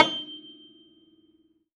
53x-pno14-G5.wav